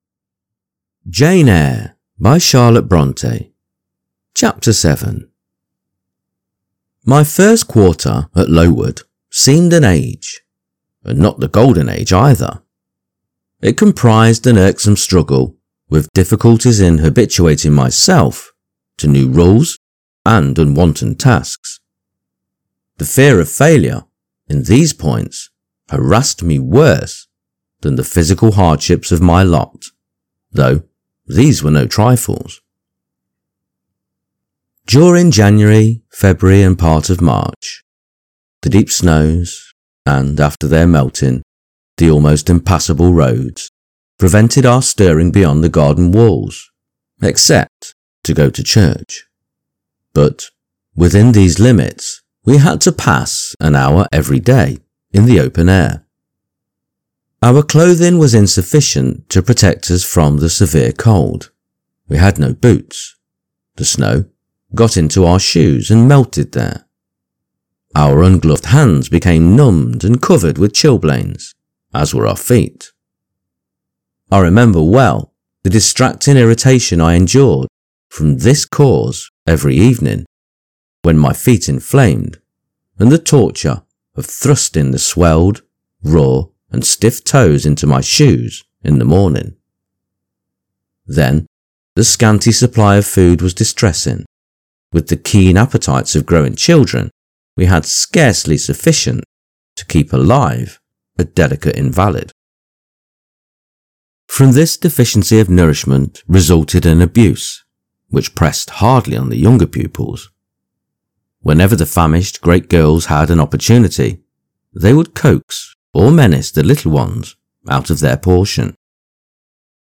Jane Eyre – Charlotte Bronte – Chapter 7 | Narrated in English - Dynamic Daydreaming